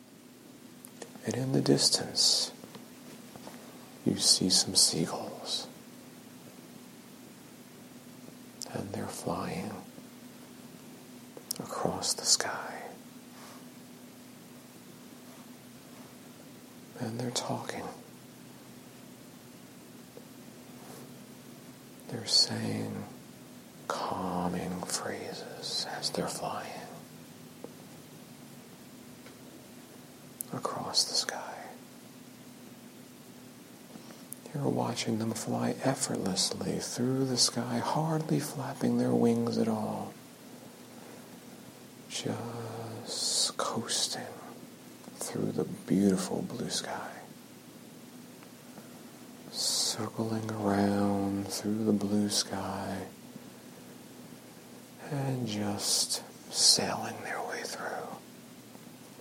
On Sunday, November 19, 2017, a group presentation which demonstrated hypnosis was held at Northwest Center for Wellness, Randolph NJ.
Here is a small sample of the presentation:
seagulls.mp3